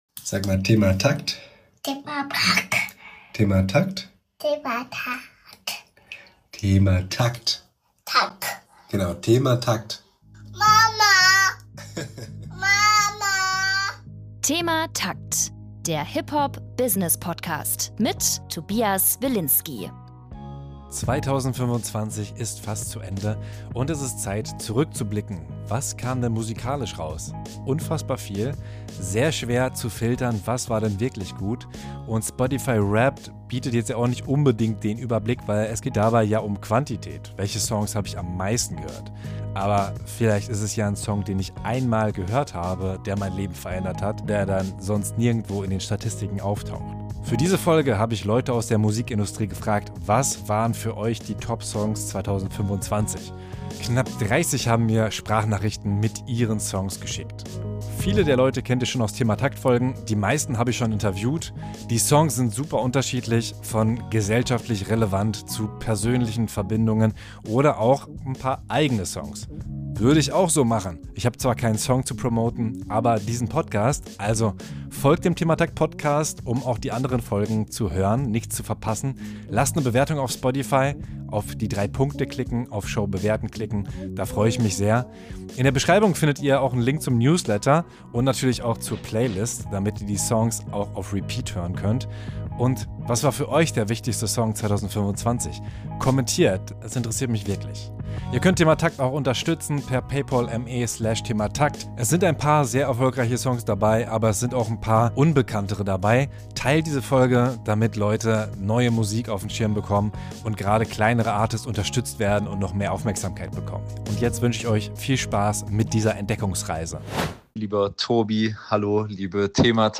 In dieser Folge stellen knapp 30 Profis aus der Musikbranche ihren persönlichen Lieblingssong des Jahres 2025 vor.
Vielen Dank an alle, die Sprachnachrichten geschickt haben und euch fürs Hören und Teilen!